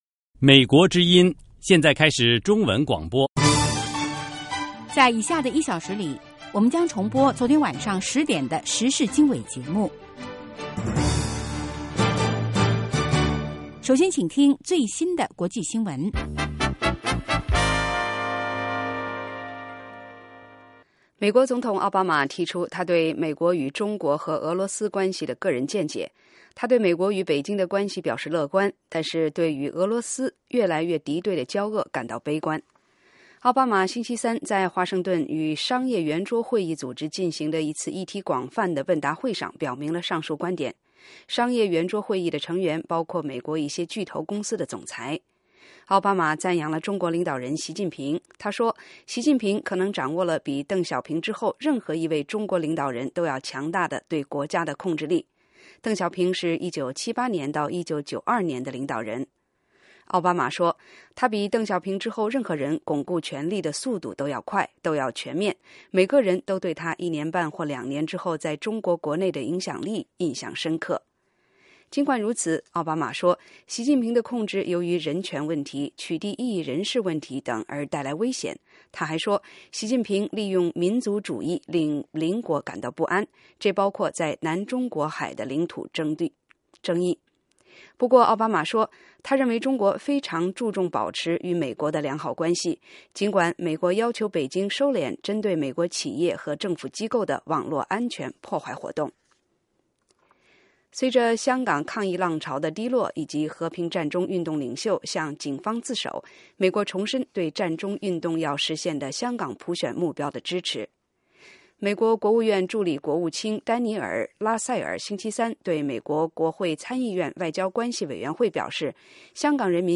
早6-7点广播节目